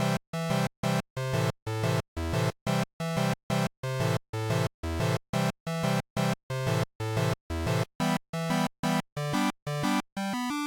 8 bit tune